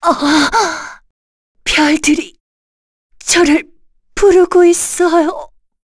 Yuria-Vox_Dead_kr_b.wav